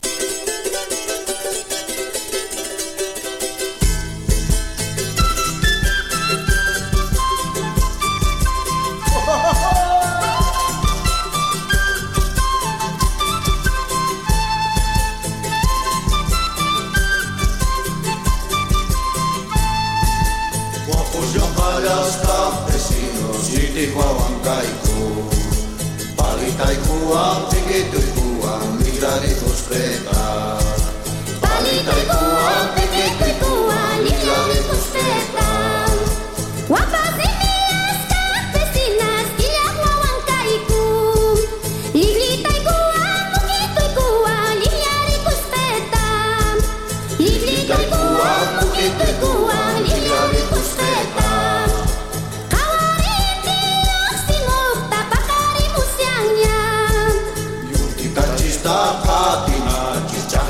強力エキセントリックな歌声のフィメール・ボーカル・ボリビアンフォークロア！